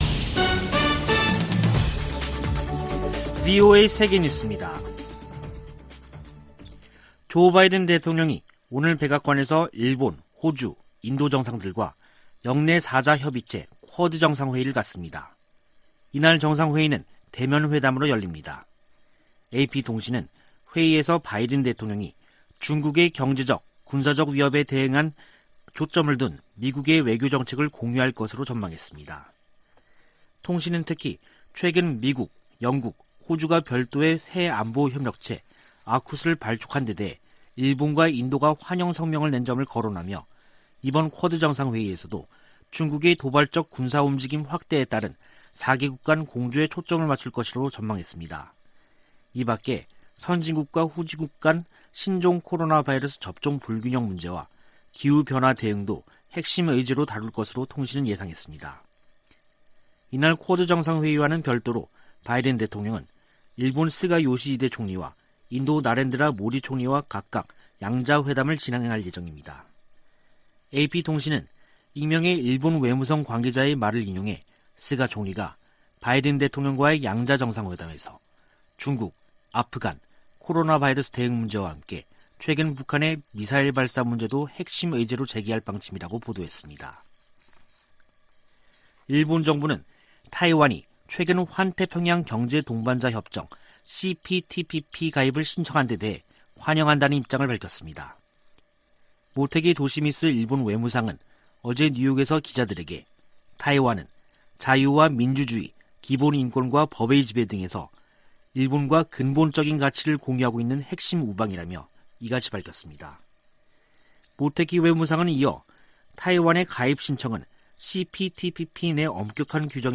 VOA 한국어 간판 뉴스 프로그램 '뉴스 투데이', 2021년 9월 24일 2부 방송입니다. 미국은 북한 식량난과 관련해 주민들의 안위를 우려하고 있다고 미 고위 당국자가 밝혔습니다. 유엔이 북한을 또다시 식량부족국으로 지정하며 코로나 여파 등으로 식량안보가 더 악화했다고 설명했습니다. 한국 전쟁 종전선언은 북한과의 신뢰 구축 목적이 크지만 미국과 한국이 원하는 결과로 이어지기는 어렵다고 미국의 전문가들이 분석했습니다.